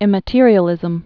(ĭmə-tîrē-ə-lĭzəm)